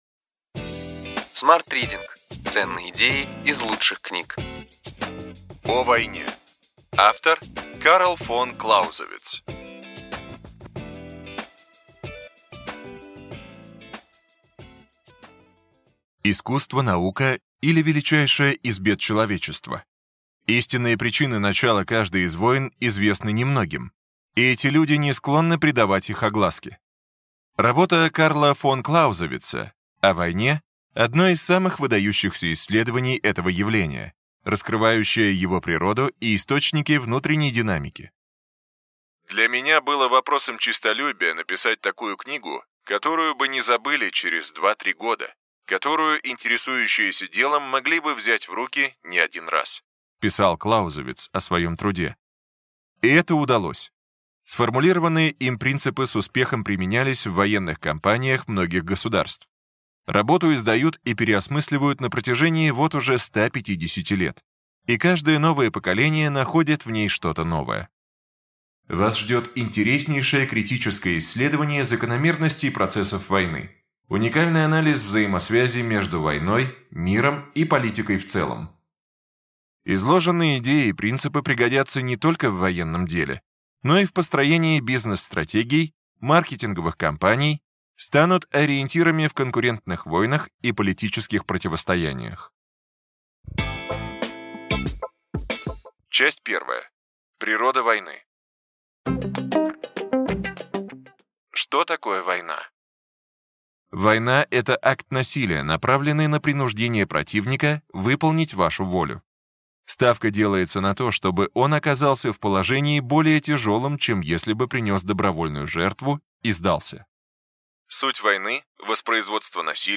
Аудиокнига Ключевые идеи книги: О войне. Карл фон Клаузевиц